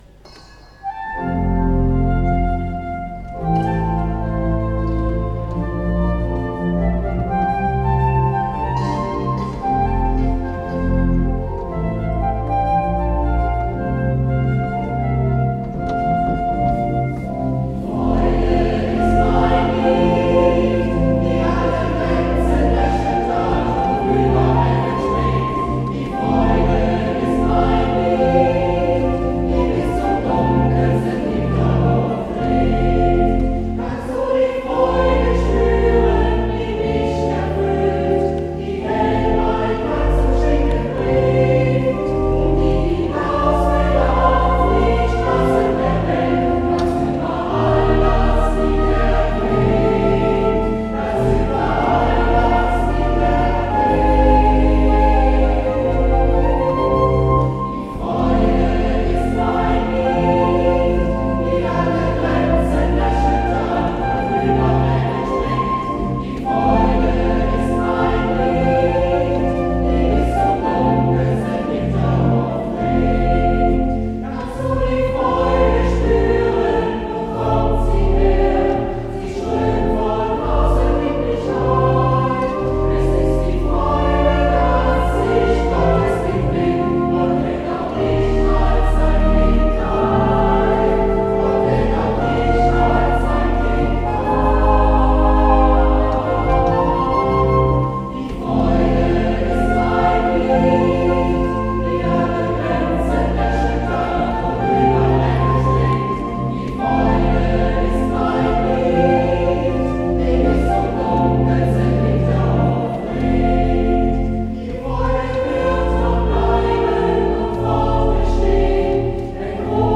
Einen krönenden Abschluss fand die Hauptoktav am Sonntag, 13. September 2009 in St. Martinus zu Aldenhoven.
Orgel
Querflöte